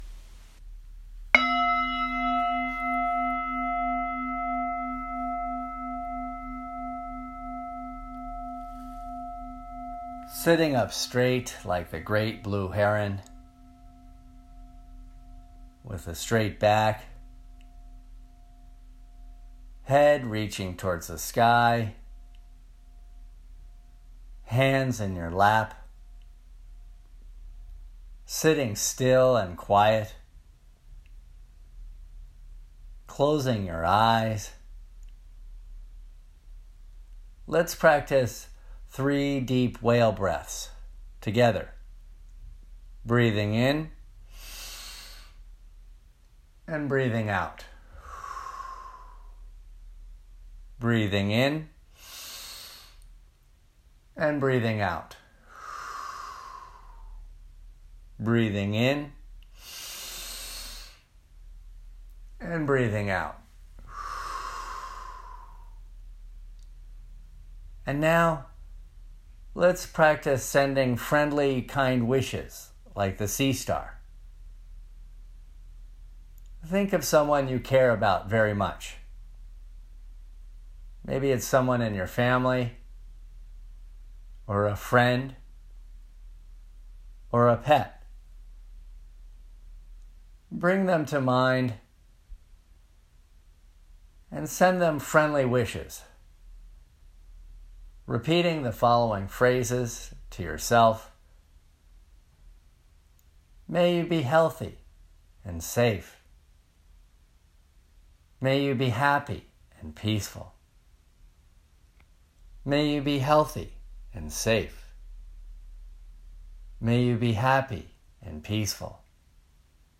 🎧 4-Minute Guided Audio: